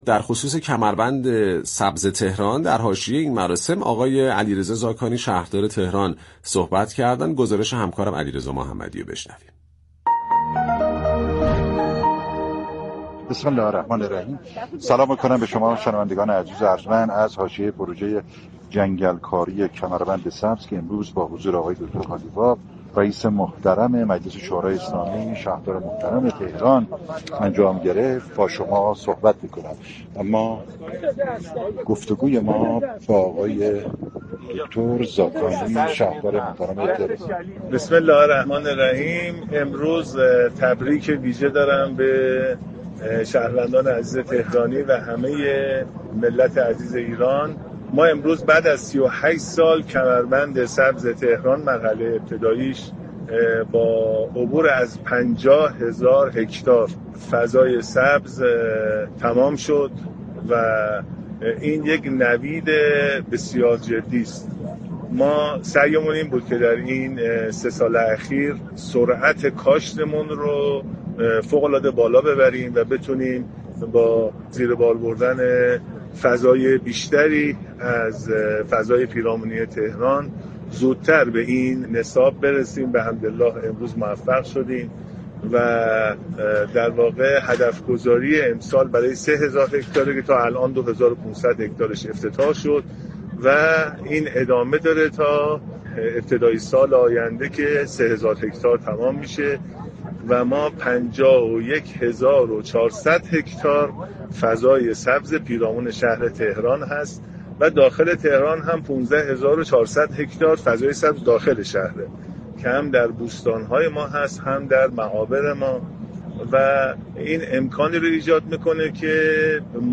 دریافت فایل به گزارش پایگاه اطلاع رسانی رادیو تهران، علیرضا زاكانی شهردار تهران در گفت و گو با گزارشگر «بام تهران» اظهار داشت: امروز بعد از 38 سال كمربند سبز پایتخت با عبور از 50 هزار هكتار فضای سبز تمام شد.